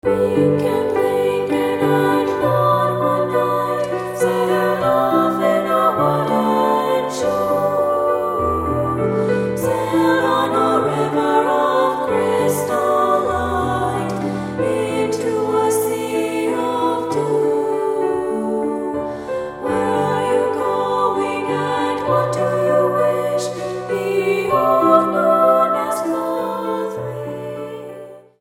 VOCALS
PIANO